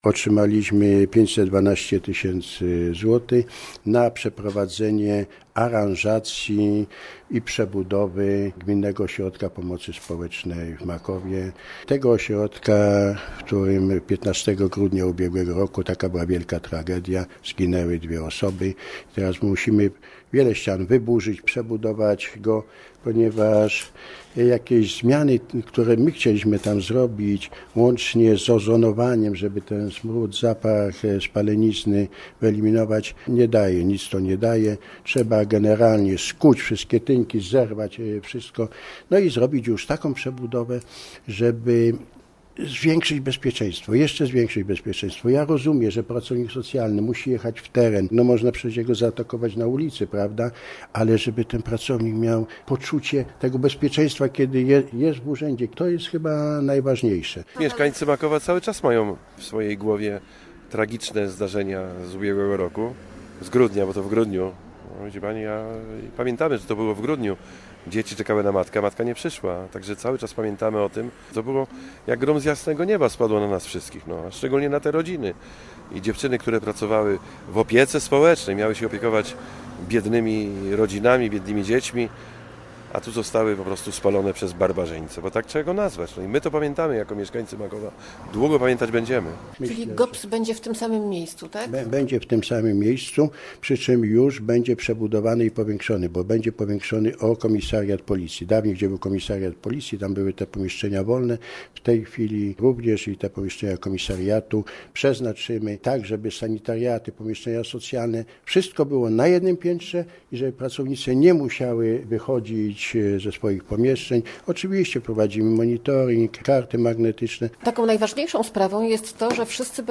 Posłuchaj rozmowy z wójtem gminy Jerzym Stankiewiczem i mieszkańcami Makowa: Nazwa Plik Autor Remont w GOPS w Makowie audio (m4a) audio (oga) Przebudowa pomieszczeń ośrodka pomocy społecznej ma potrwać do końca tego roku.